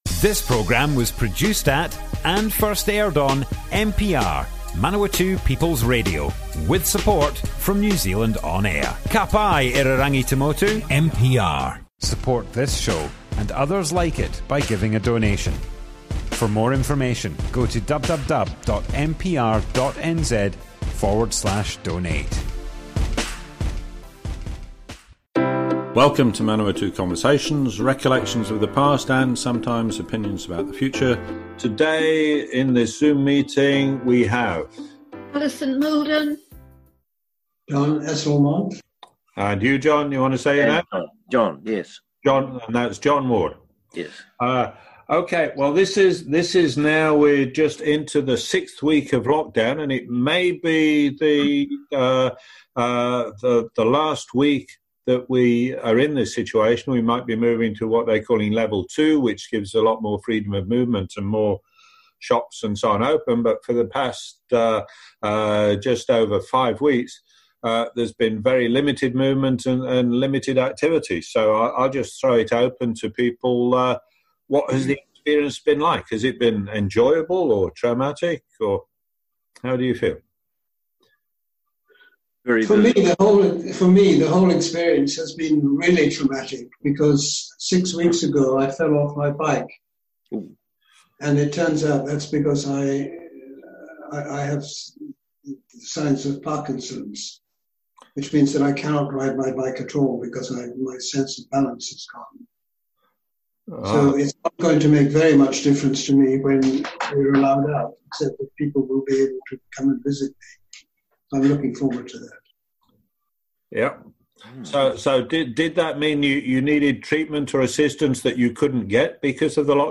Covid-19 Lockdown - Oral history
Broadcast on Manawatu People's Radio, 26th May 2020.